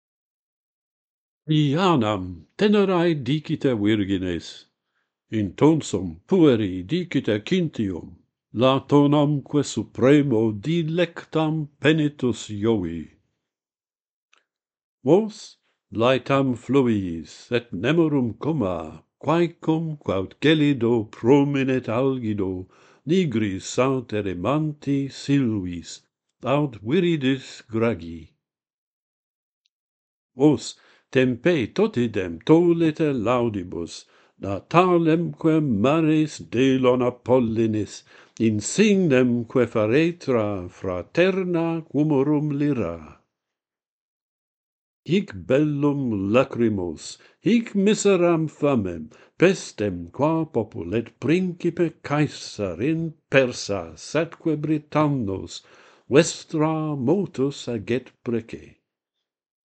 Diana and Apollo: a hymn - Pantheon Poets | Latin Poetry Recited and Translated